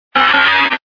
Cri de Ramboum dans Pokémon Diamant et Perle.